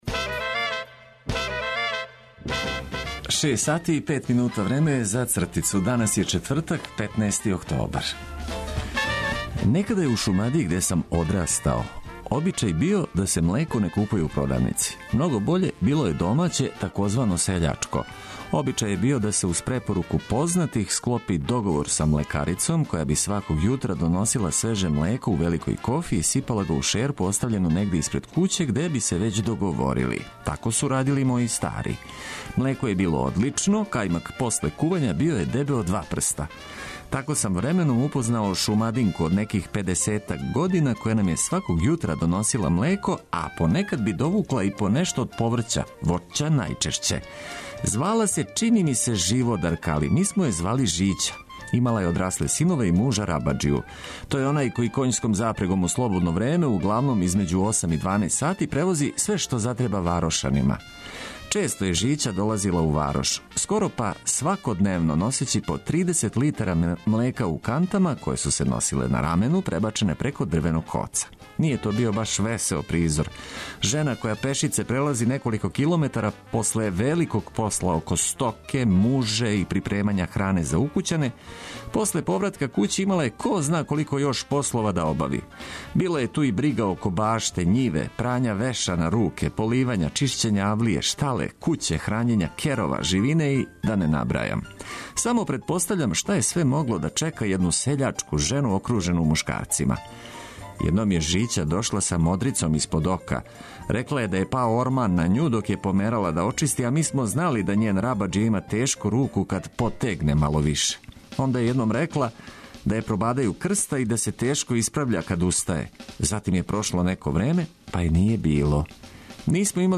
Корисне информације, добра музика и одлично расположење током јутра обећавају успешно започињање радног дана.